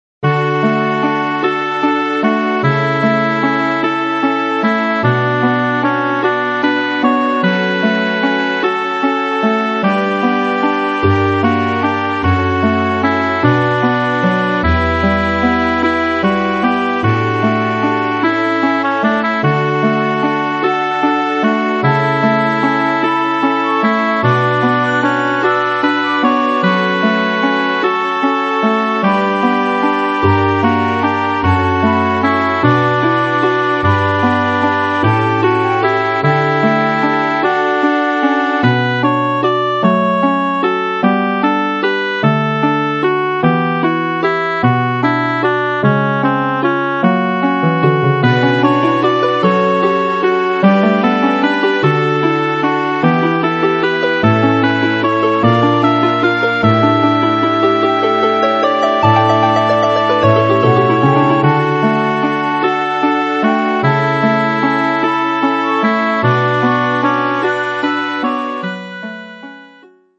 022 　のどかな感じ2（D） 06/07/25